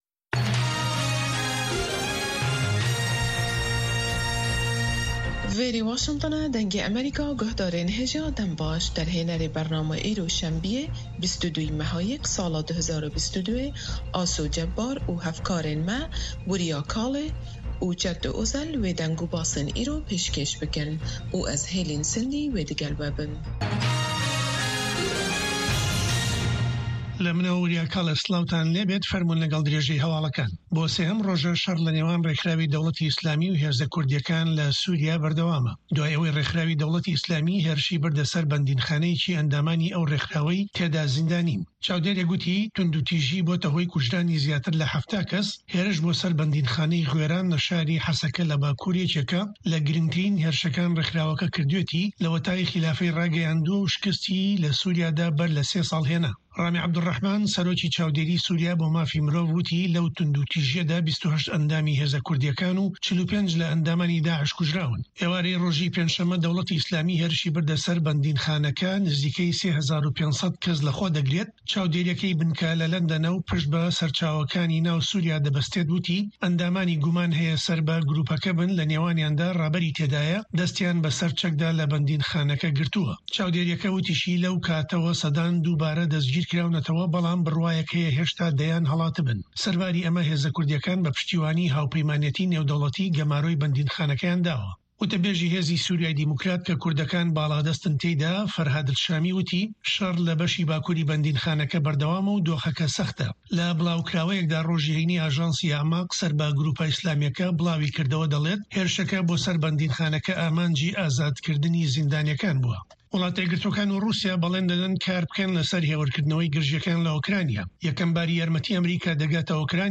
هەواڵەکانی 1 ی پاش نیوەڕۆ
هەواڵە جیهانیـیەکان لە دەنگی ئەمەریکا